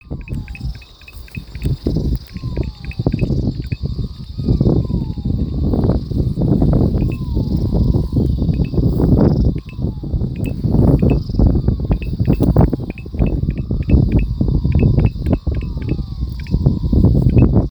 Ranita Llorona (Physalaemus albonotatus)
Sonido en segundo plano
Localidad o área protegida: Colonia Carlos Pellegrini
Condición: Silvestre
Certeza: Fotografiada, Vocalización Grabada